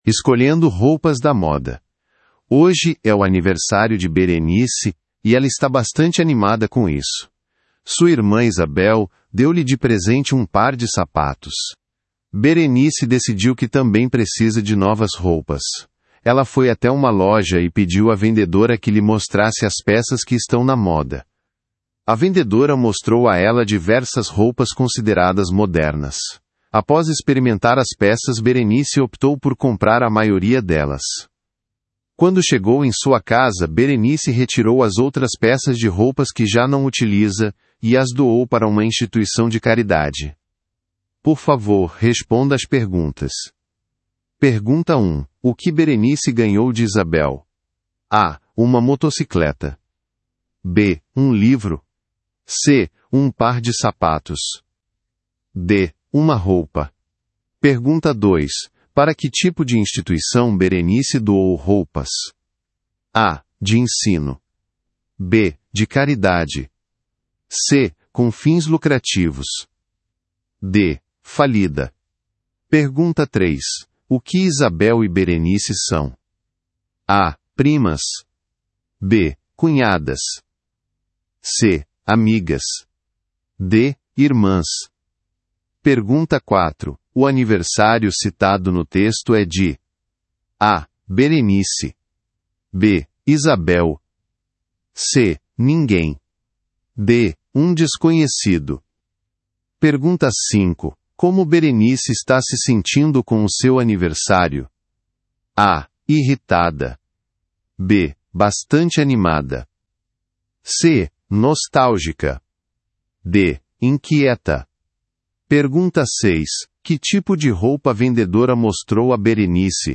Brasile